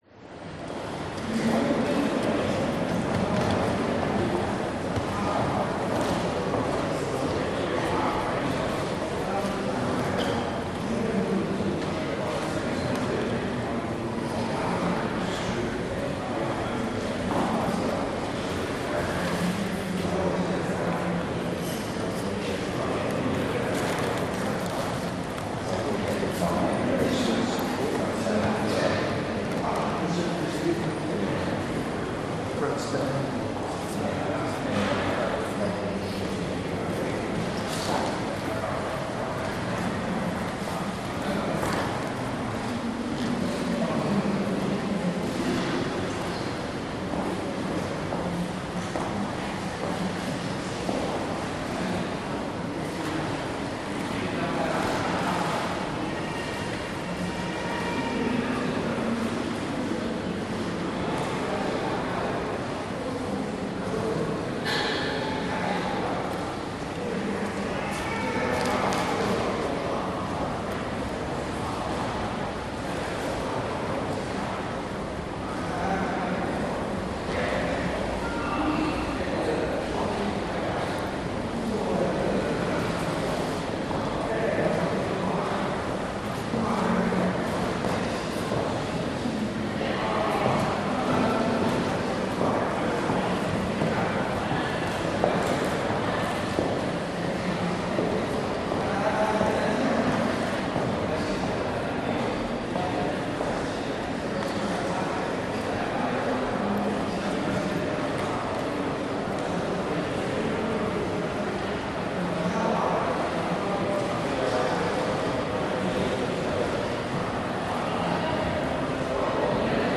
Художественная галерея Нового Южного Уэльса, залы с экспозицией, посетители прогуливаются и беседуют, старинный скрипучий пол, Сидней, Австралия